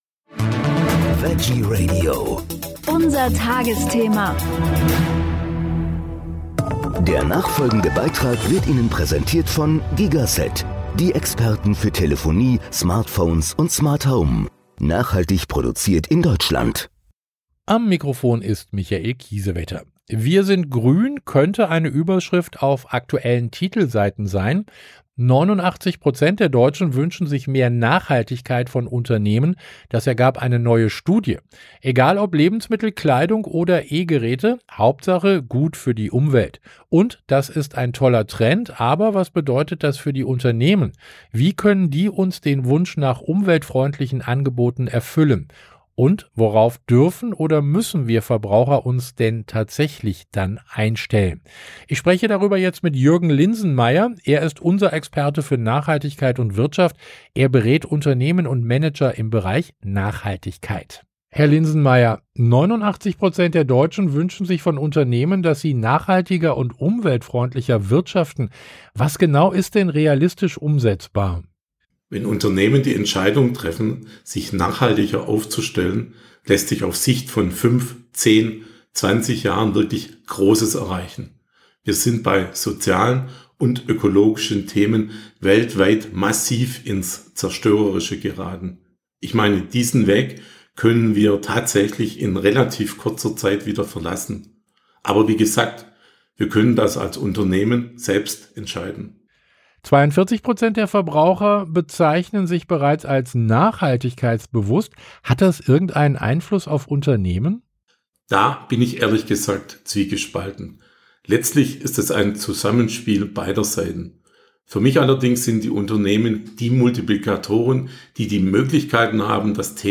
Er ist Experte für Nachhaltigkeit und Wirtschaft und berät Unternehmen und Manager im Bereich Nachhaltigkeit.